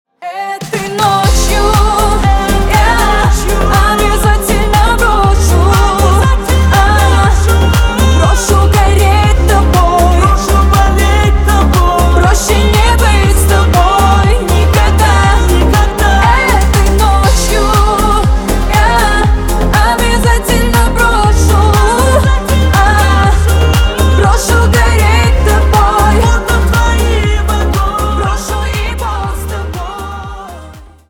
на русском на бывшего грустные